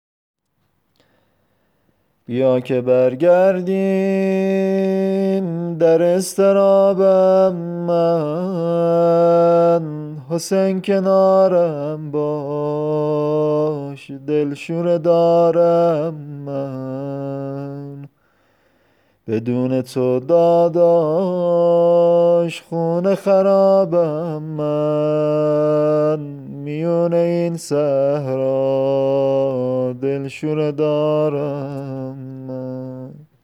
(به سبک : به سمت گودال از خیمه دویدم من)